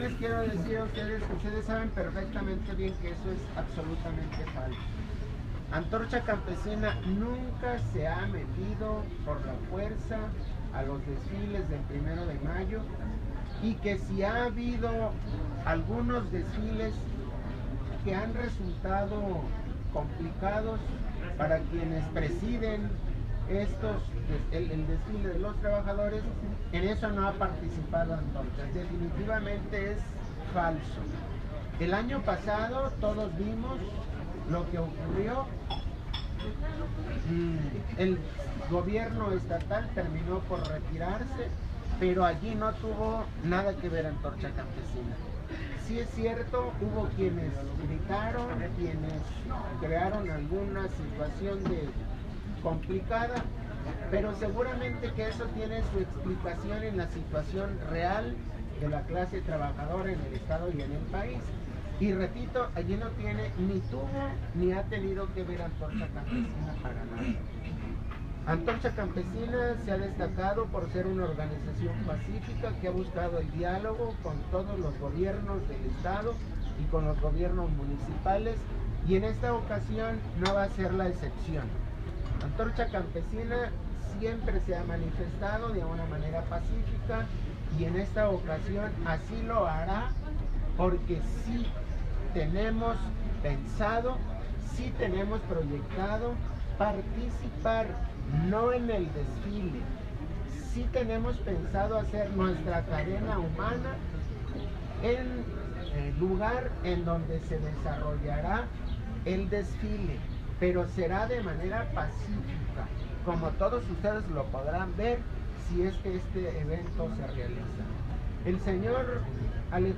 Antorcha Rueda De Prensa